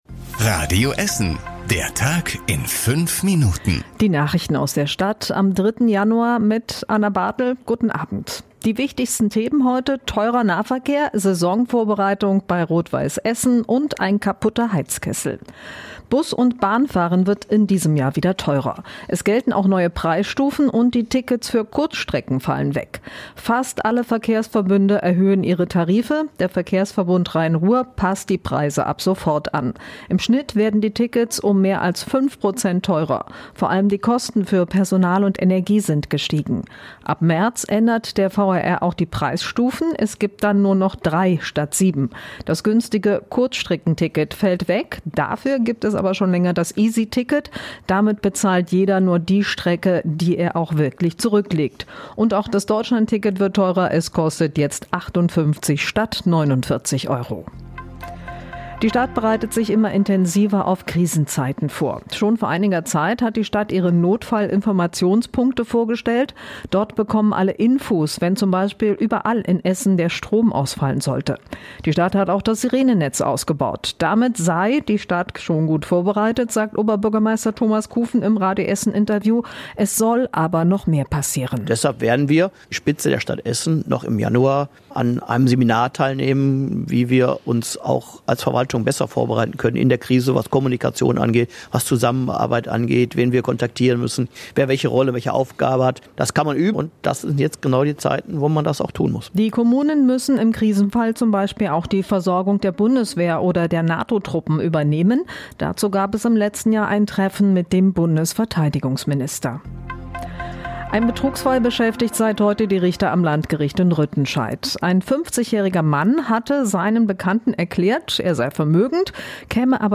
Unsere Nachrichtenredakteure fassen den Tag für Euch noch mal zusammen.
Täglich um 19.30 bei uns im Radio.